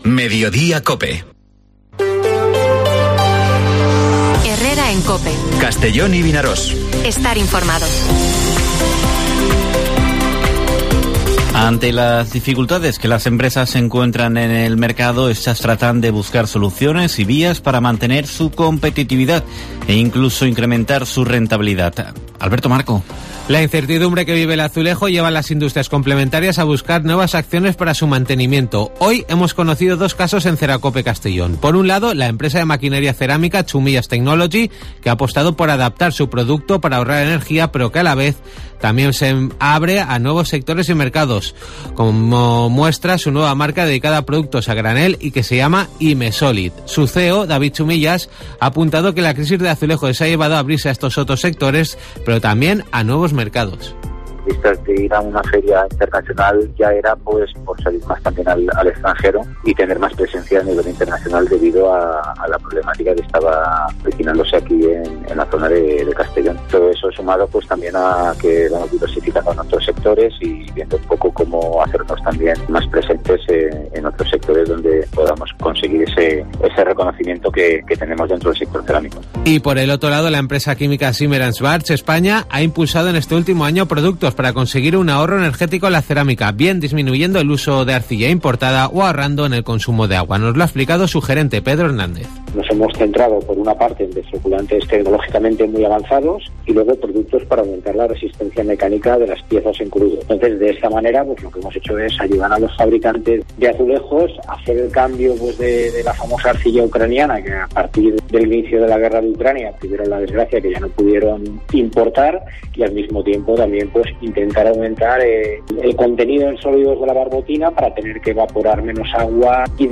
Informativo Mediodía COPE en la provincia de Castellón (17/01/2023)